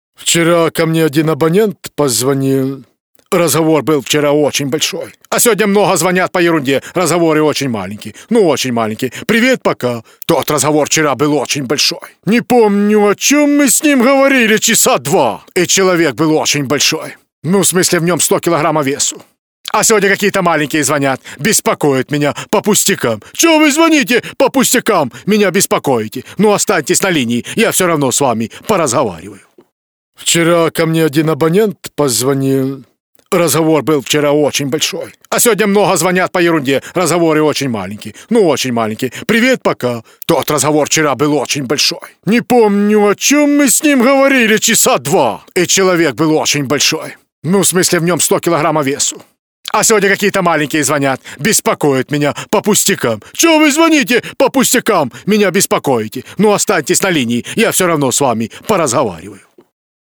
Parodiya_na_Karceva.mp3